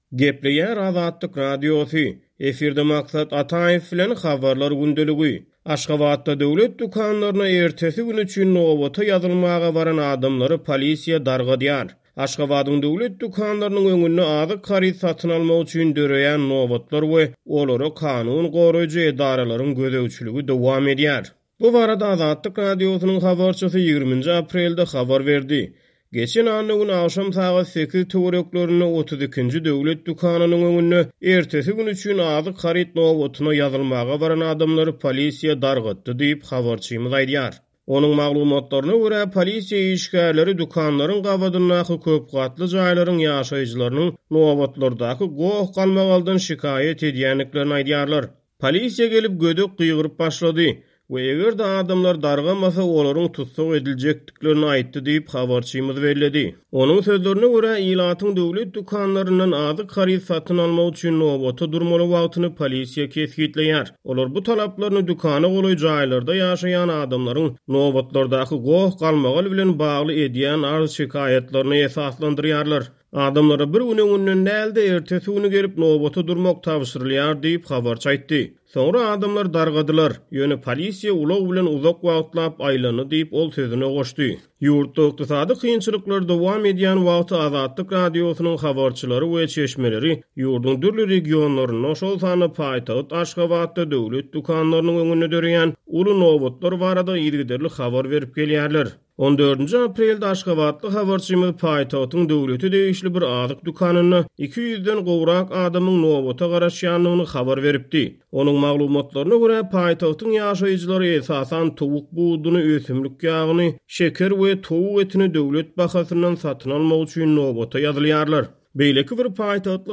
Aşgabadyň döwlet dükanlarynyň öňünde azyk haryt satyn almak üçin döreýän nobatlar we olara kanun goraýjy edaralaryň gözegçiligi dowam edýär.  Bu barada Azatlyk Radiosynyň habarçysy 20-nji aprelde habar berdi.